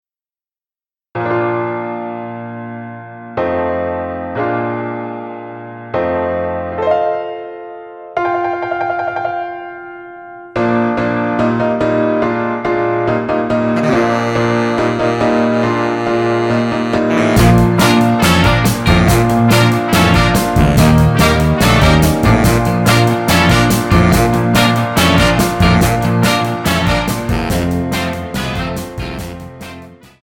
Tonart:Bb ohne Chor